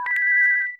made terminal sounds quieter